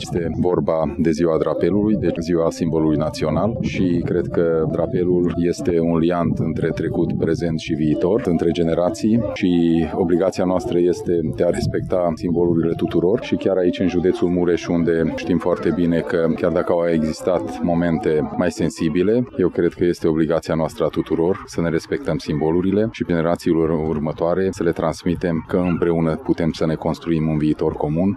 De Ziua Drapelului, celebrată pe 26 iunie, Garnizoana Târgu Mureş şi Instituţia Prefectului – Judeţul Mureş, cu sprijinul Primăriei municipiului Târgu Mureş și al Consiliulul Judeţean Mureş au organizat o ceremonie publică de înălţare a drapelului național al României pe catargul din Cetatea Medievală din Târgu Mureș.
Președintele CJ Mureș, Peter Ferenc a subliniat, în discursul său, necesitatea respectării simbolurilor naționale: